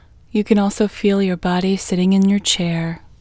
LOCATE OUT English Female 3